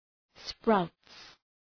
Shkrimi fonetik {spraʋts}